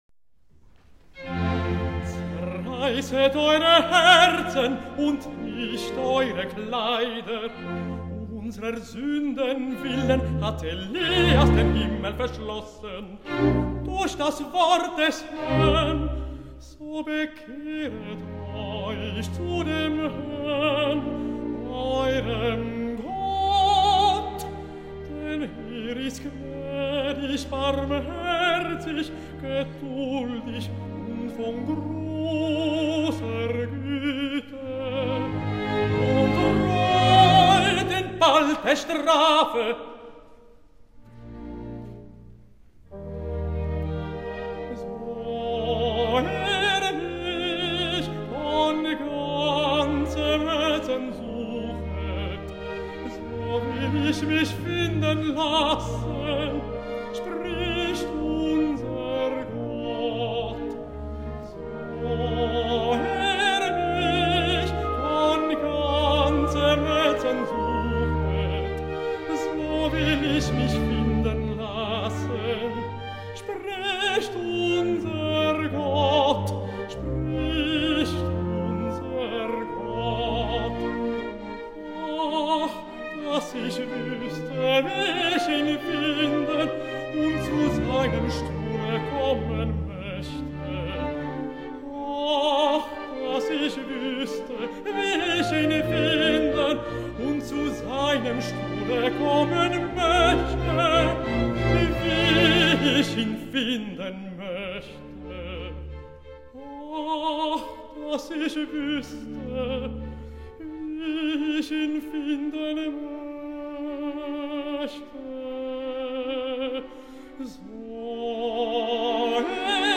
-Rezitativ & Arie : So. ihr mich..."
Tenor
LUZERNER SINFONIEORCHESTER
Live recording